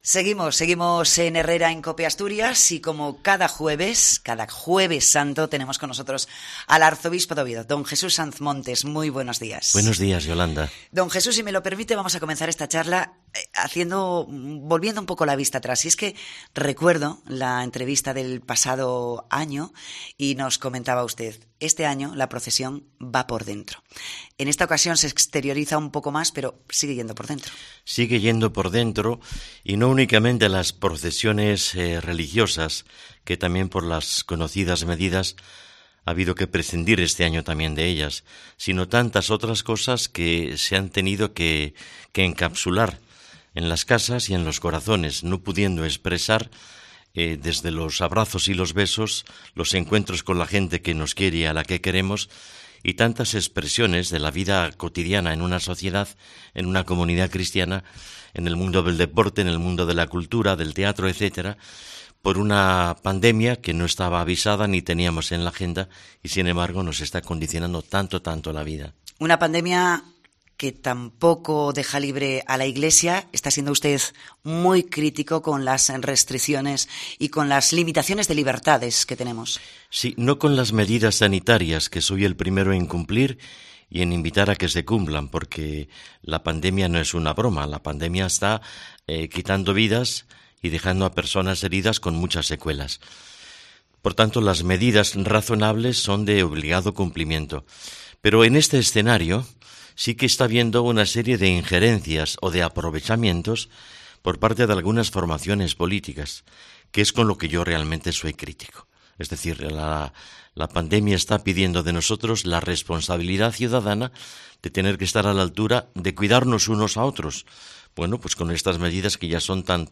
Entrevista al Arzobispo de Oviedo, mons. Jesús Sanz Montes
El Arzobispo de Oviedo, monseñor Jesús Sanz Montes, ha pasado este Jueves Santo por los micrófonos de COPE Asturias antes de desplazarse a Covadonga, donde presidirá los oficios de Semana Santa, que podrán seguirse en directo a través del canal de youtube del Santuario.